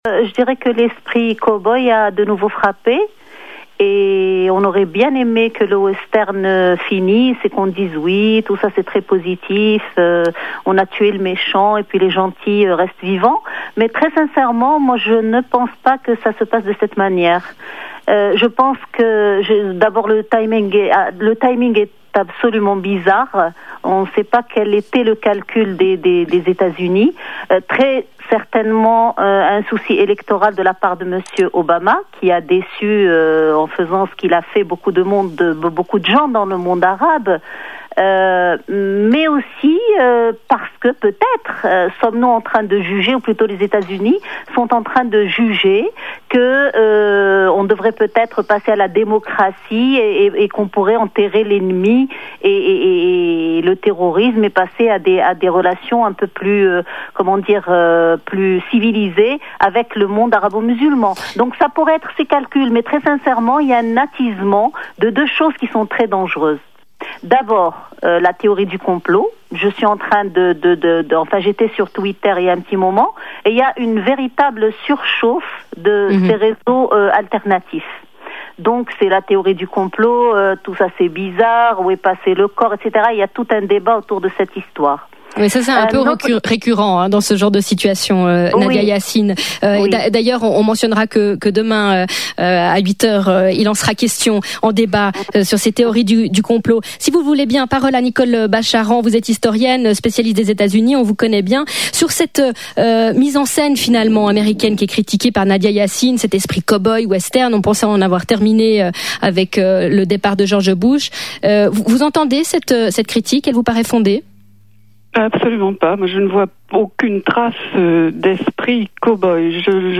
Débat entre Nadia Yassine et Nicole Bacharan: La mort de Ben Laden, positif ou non? (RSR)
Nadia Yassine, marocaine, porte parole du mouvement islamiste Al Adl Wal Ihsane (Justice et Spiritualité) & Nicole Bacharan, politologue franco-américaine, spécialiste de la société américaine